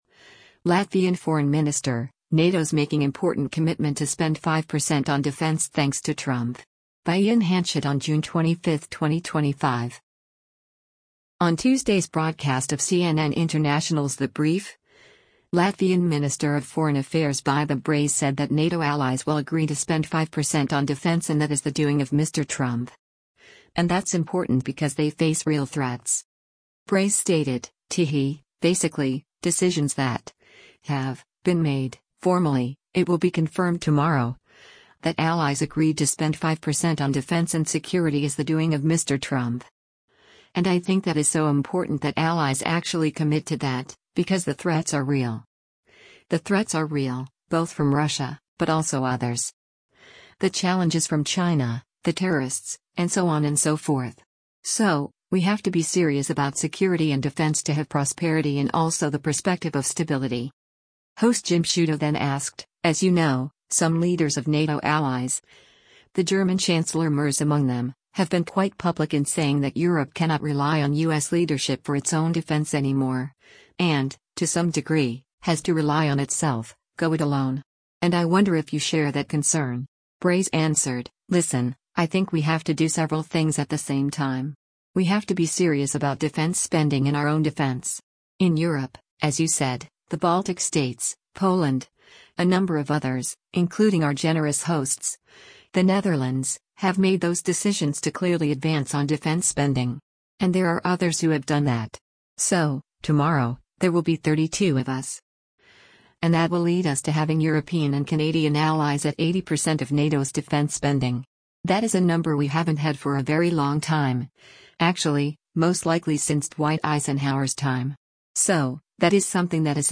On Tuesday’s broadcast of CNN International’s “The Brief,” Latvian Minister of Foreign Affairs Baiba Braže said that NATO allies will agree to spend 5% on defense and that “is the doing of Mr. Trump.”
Host Jim Sciutto then asked, “As you know, some leaders of NATO allies, the German Chancellor Merz among them, have been quite public in saying that Europe cannot rely on U.S. leadership for its own defense anymore, and, to some degree, has to rely on itself, go it alone. And I wonder if you share that concern.”